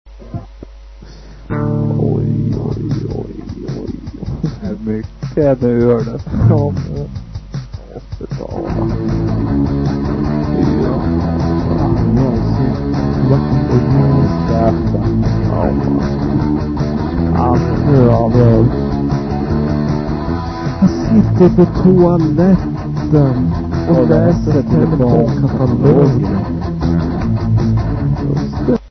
Guitars, Vocals
Drums, Vocals, Bass